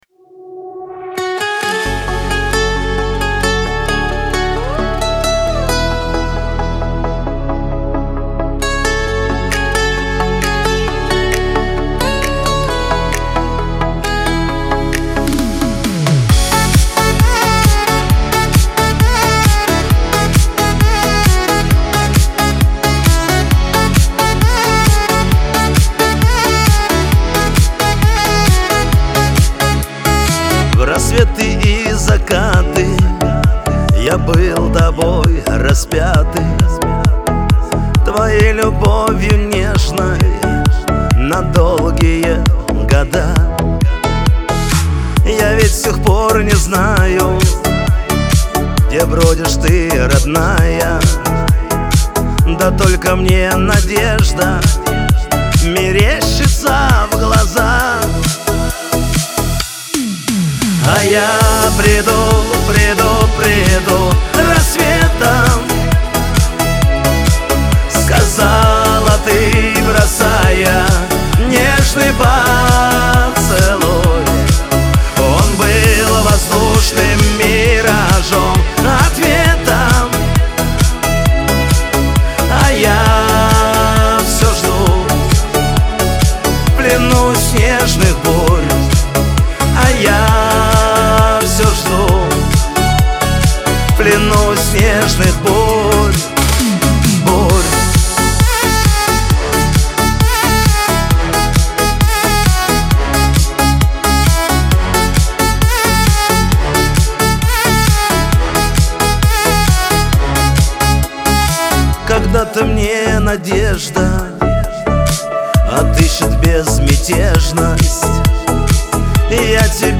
эстрада
диско
dance